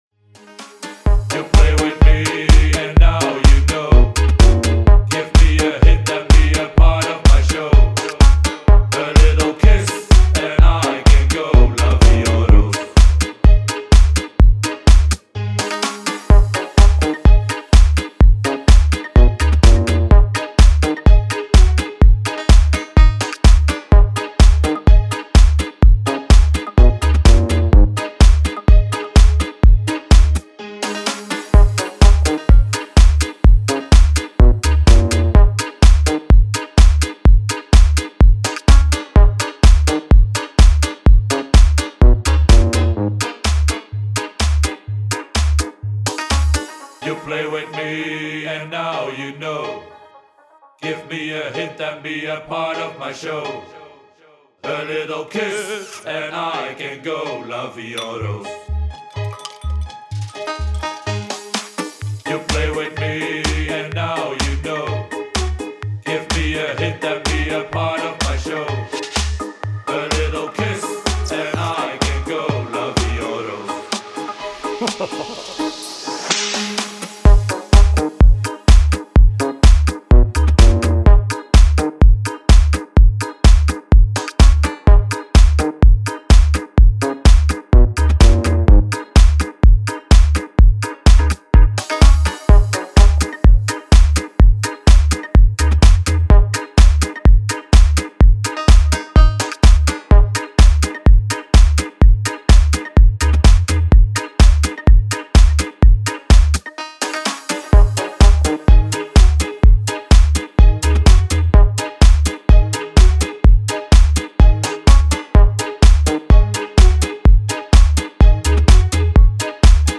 Style: Tech House / Minimal